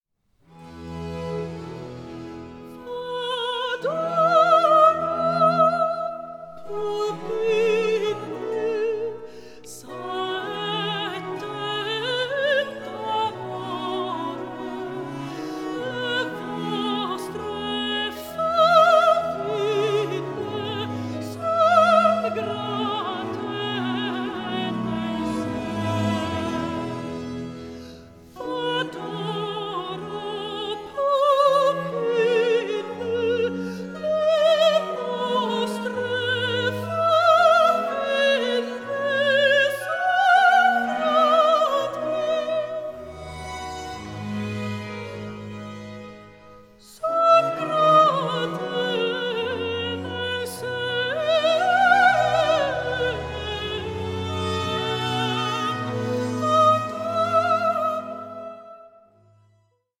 INSTRUMENTALISTS PUT CENTRE STAGE
Baroque ensembles